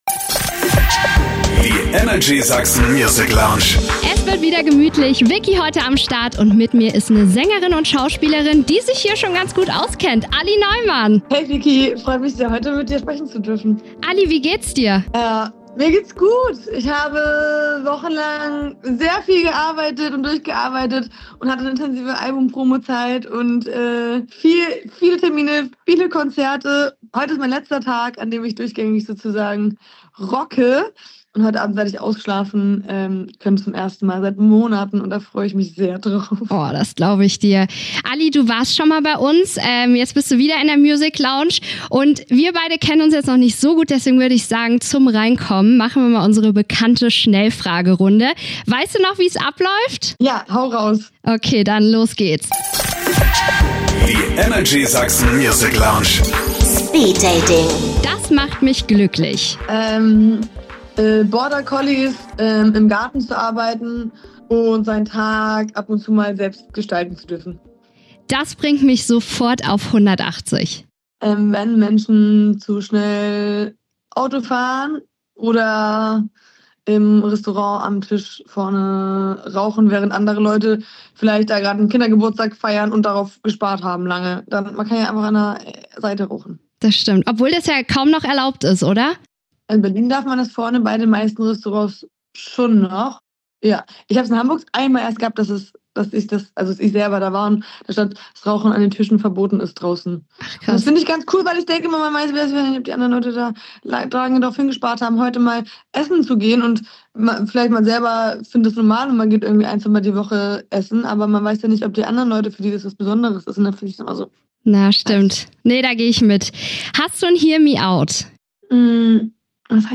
Wie ein Gespräch mit deiner besten Freundin - genau so fühlt sich die Folge mit Alli an !